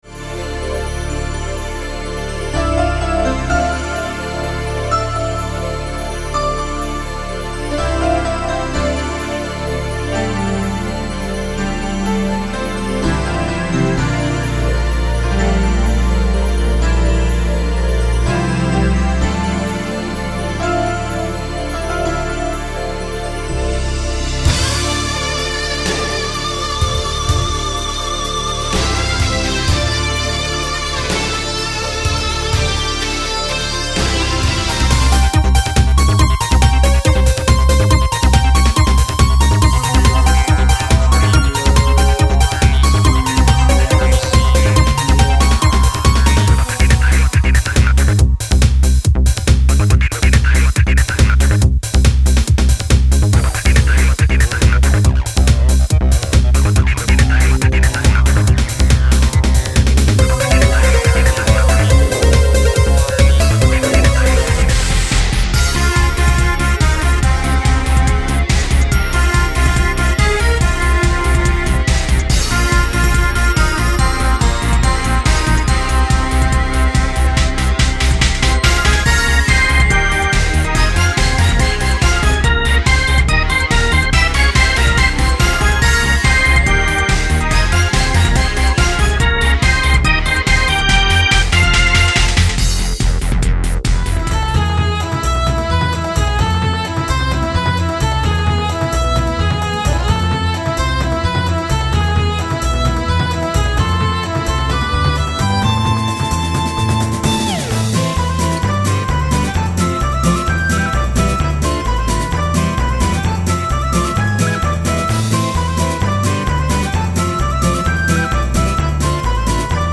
どこらへんがさすらいなのかわからないほど、スピード感あふれるArrange作品です